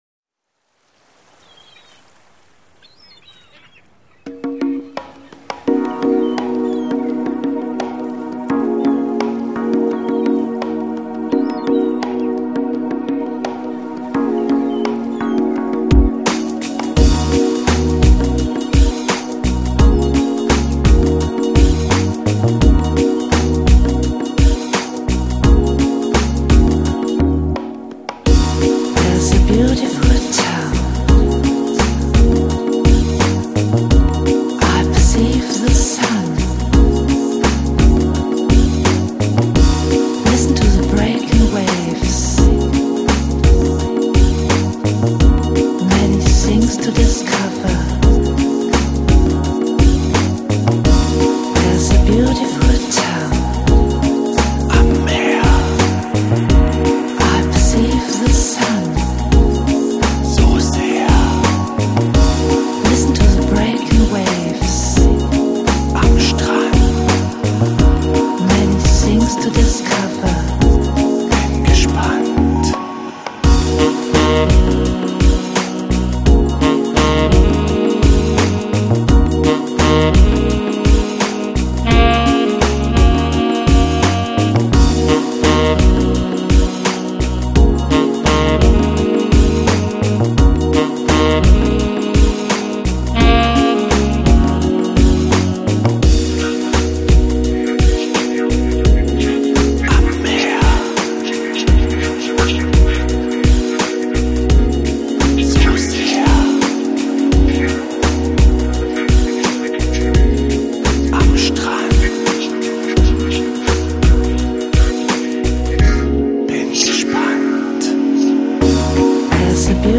Lounge, Chill Out, Downtempo, Lounge Pop Год издания